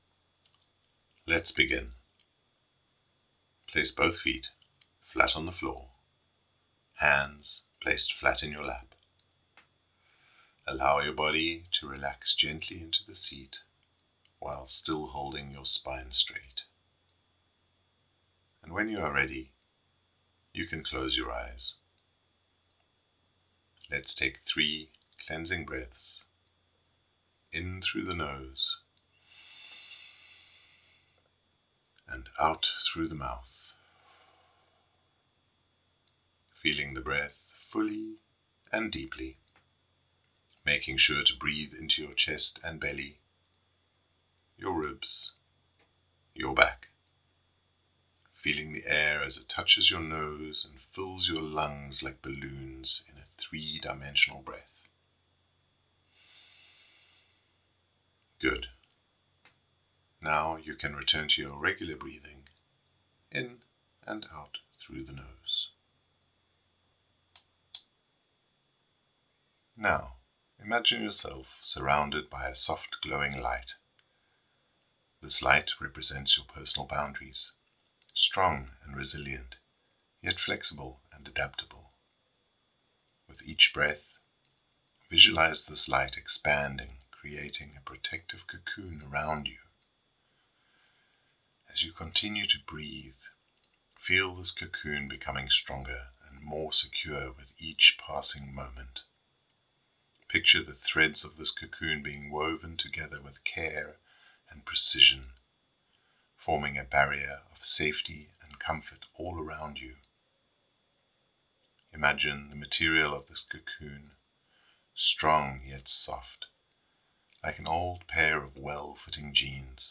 Weaving Safety Meditation
WS23-meditation-Weaving-Safety.mp3